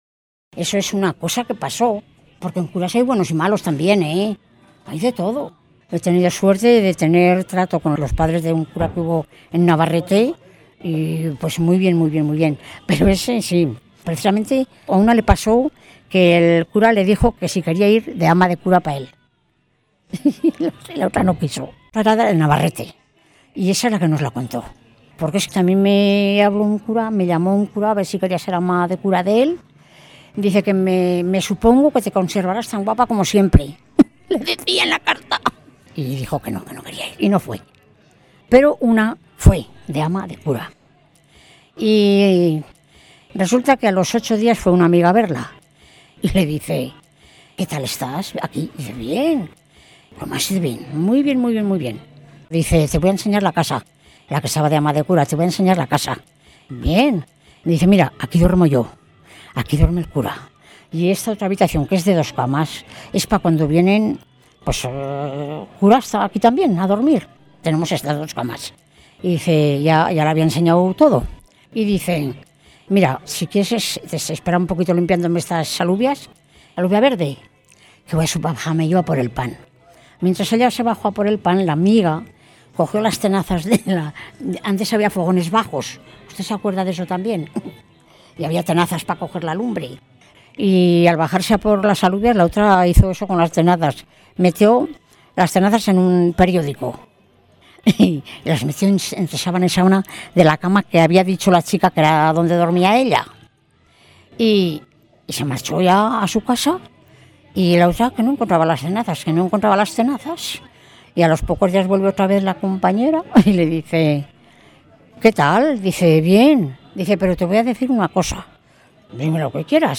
Clasificación: Cuentos
Lugar y fecha de recogida: Lardero, 30 de abril de 2003